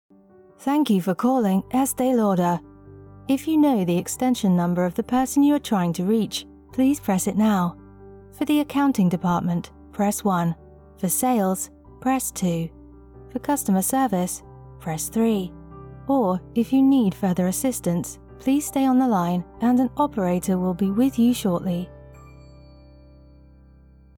Female
Phone Greetings / On Hold
British I Estee Lauder
Words that describe my voice are Conversational, Believable, Engaging.
All our voice actors have professional broadcast quality recording studios.